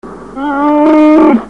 "Chewbacca roaring"